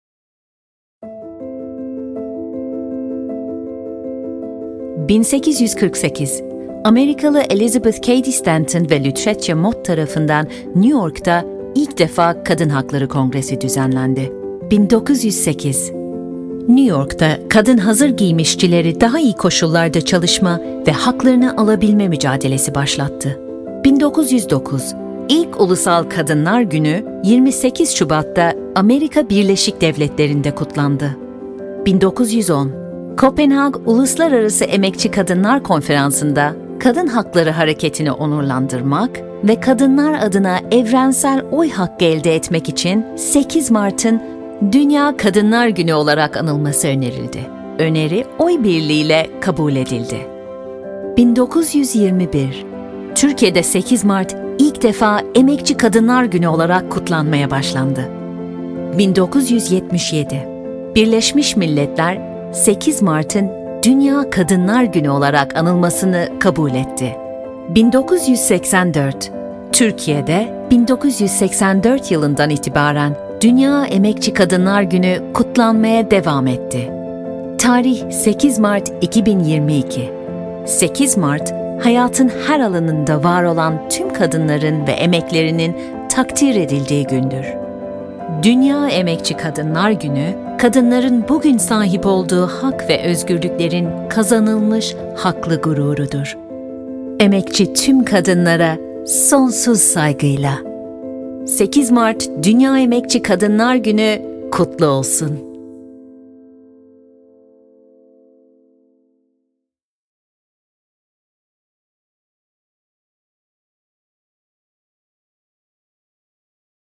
Amable, Cálida, Empresarial
Corporativo